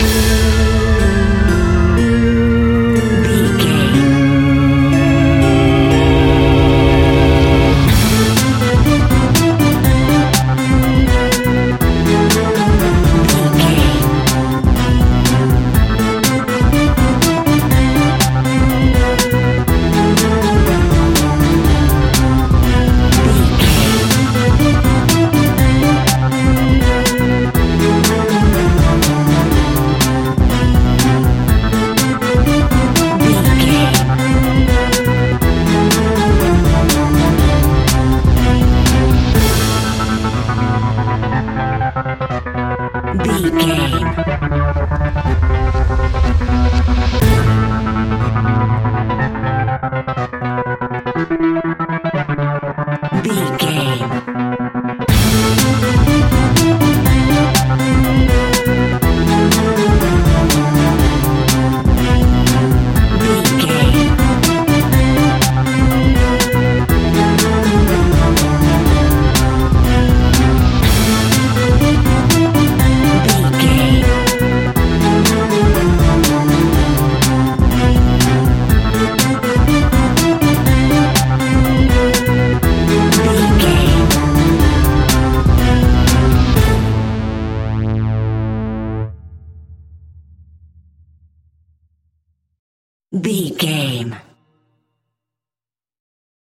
Aeolian/Minor
scary
eerie
groovy
funky
electric organ
synthesiser
drums
strings
percussion
spooky
horror music